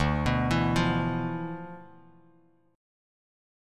Dm6add9 chord